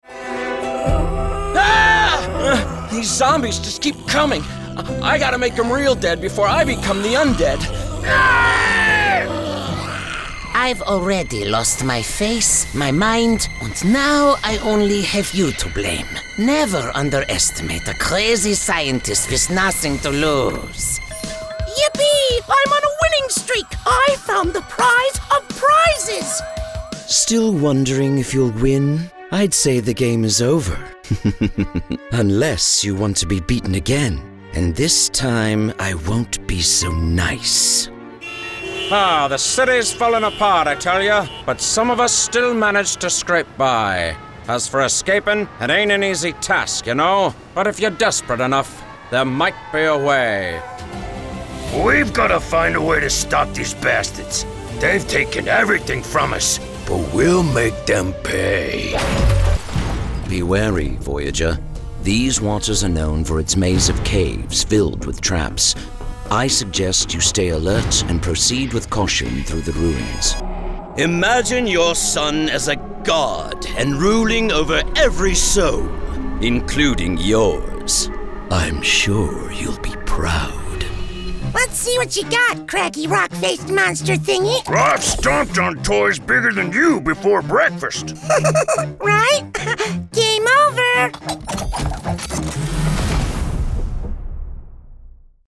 Has Own Studio
Accents: british rp | natural german | natural irish | character manchester | natural midwestern us | natural new york | character scottish | character southern us | natural standard british | natural
GAMING